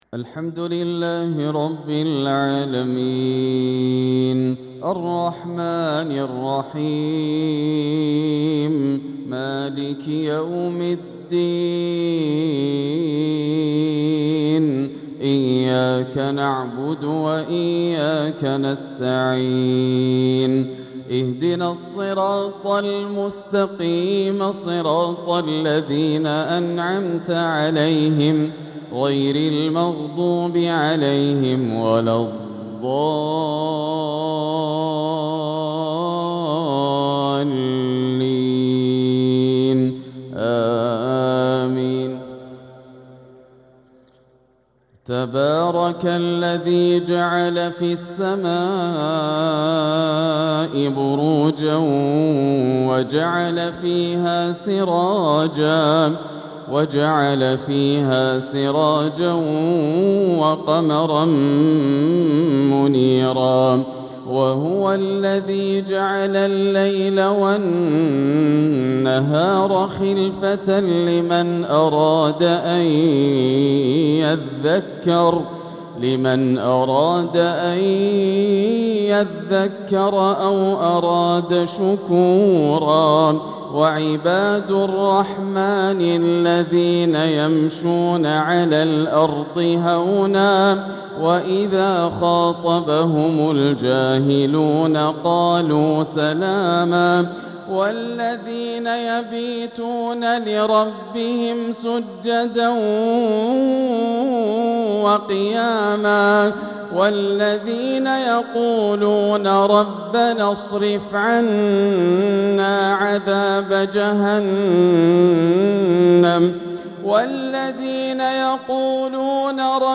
تلاوة في غاية البهاء لخواتيم سورة الفرقان | عشاء السبت 8-2-1447هـ > عام 1447 > الفروض - تلاوات ياسر الدوسري